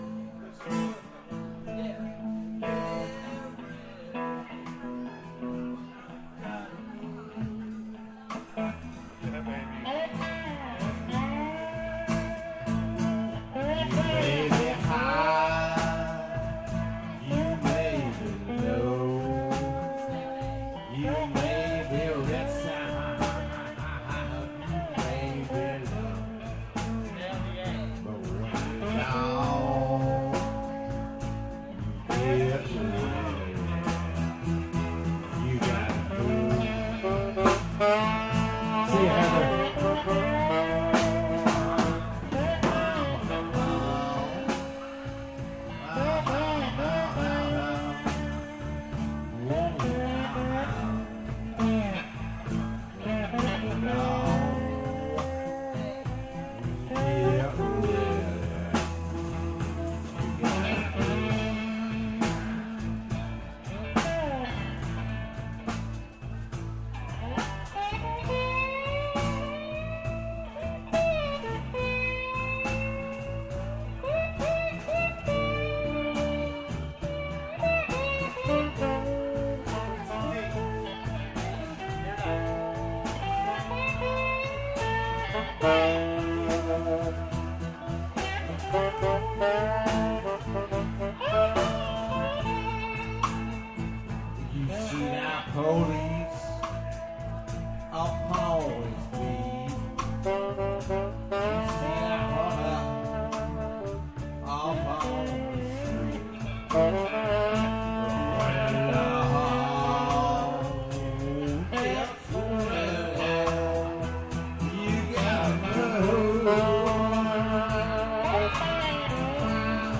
vocals & piano
guitar & vocals
horns, piano & vocals
bass, piano & vocals
drums
lead guitar & vocals
Live music on the deck at Molly's May 24 2012
One of the pleasant surprises on the deck this year was the
visitor musicians that joined the band for just a song or two.